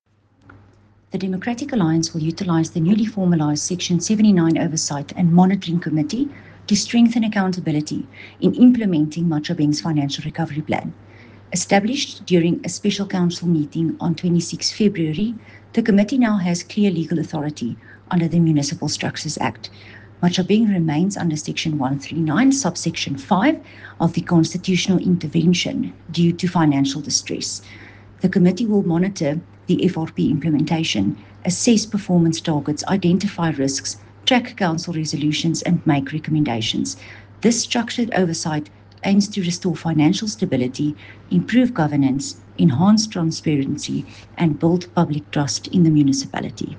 Issued by Cllr. René Steyn – DA Councillor Matjhabeng Local Municipality
Afrikaans soundbites by Cllr René Steyn and